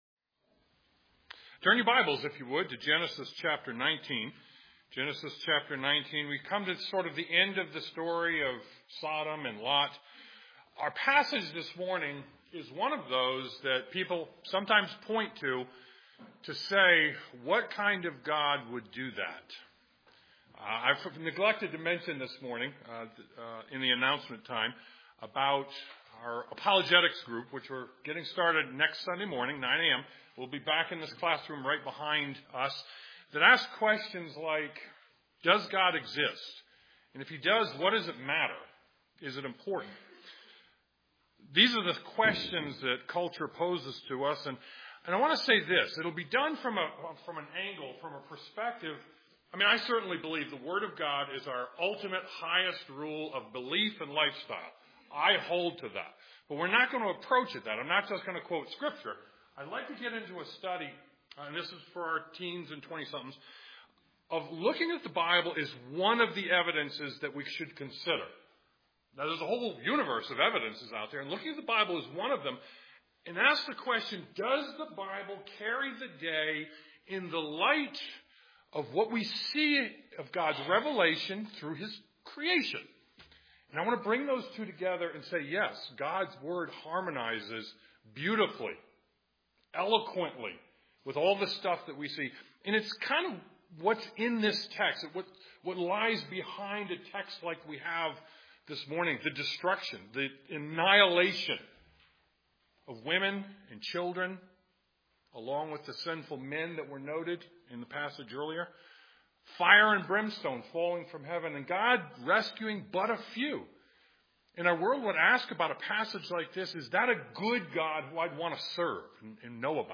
Genesis 19:23-38 Service Type: Sunday Morning Genesis 19:23-38 God’s future judgment and past grace should inform our choices in the present.